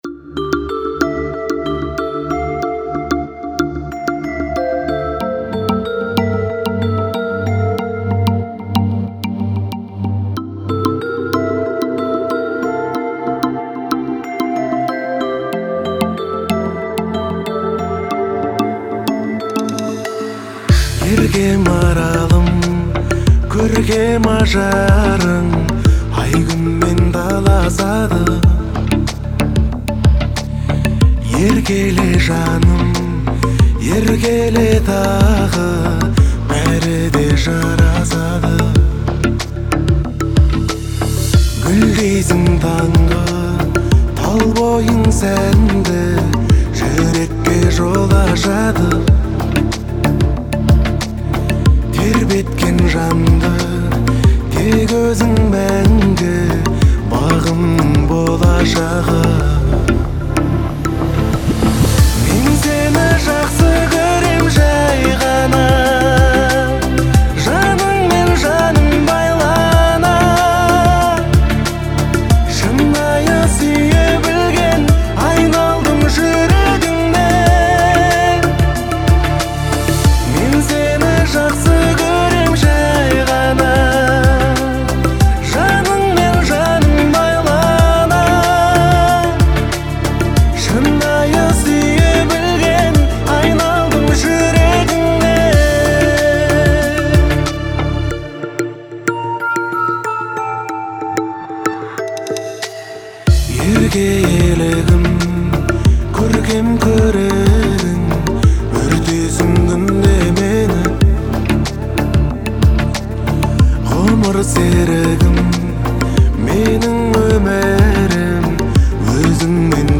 это проникновенная казахская песня в жанре поп